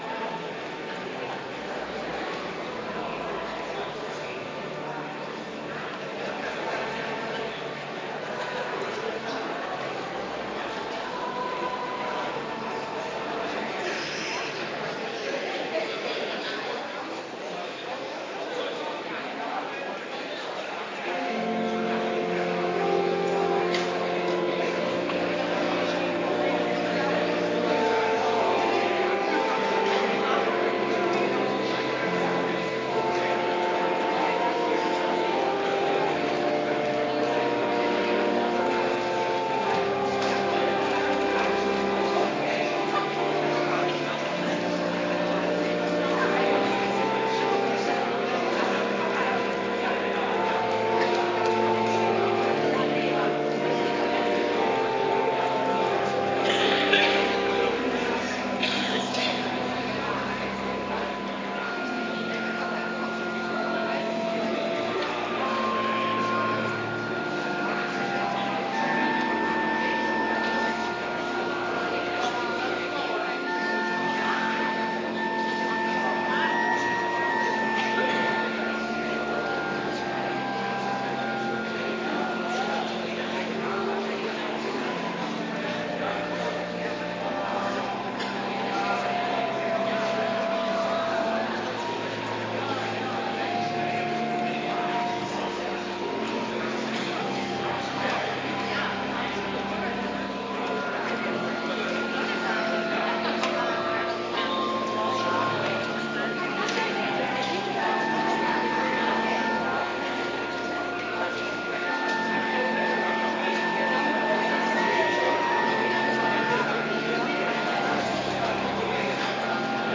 Kerkdiensten
Adventkerk Zondag week 31